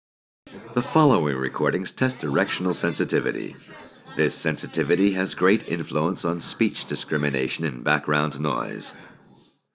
Aquí escucharemos a un hombre hablando en un ambiente con conversaciones de fondo, grabado mediante diferentes configuraciones de micrófonos.
La segunda corresponde a un sistema GSC y la última, la mejor, corresponde a un sistema AEC-GSC.
robust GSC output.wav